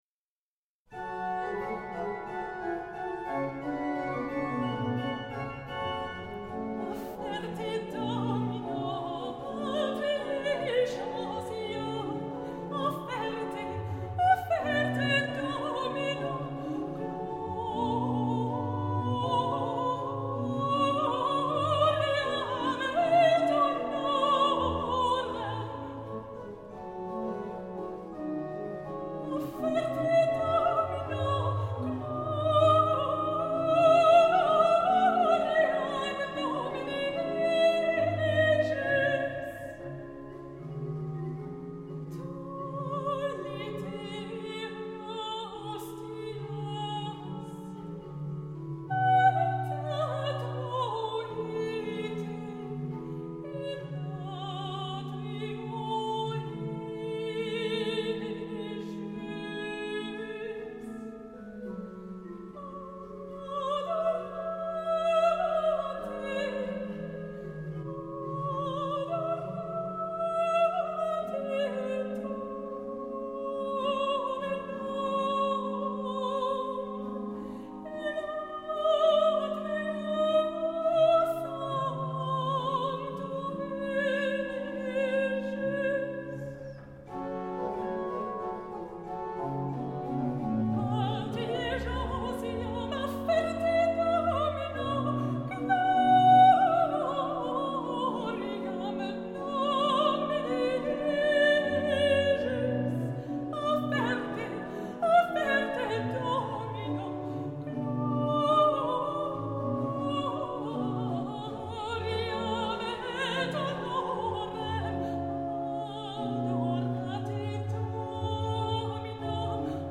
Petit motet
~1600 (Baroque)